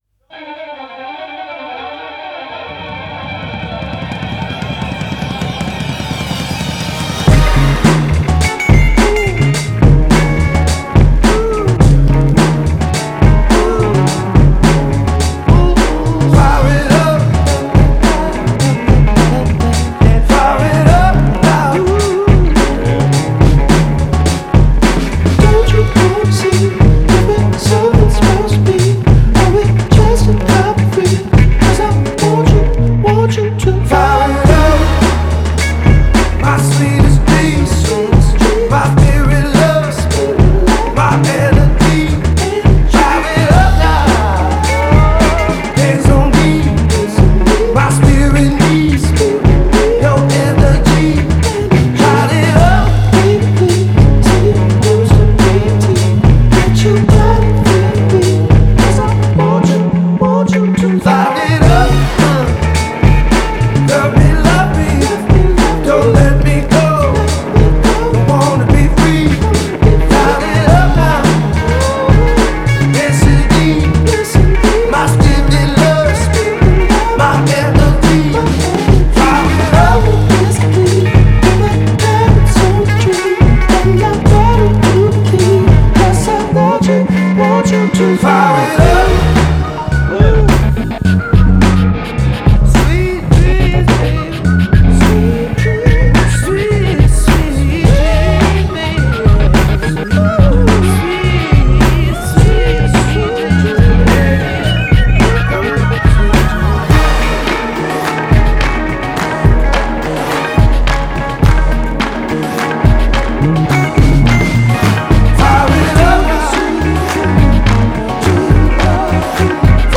энергичная и зажигательная композиция
выполненная в жанре R&B с элементами попа.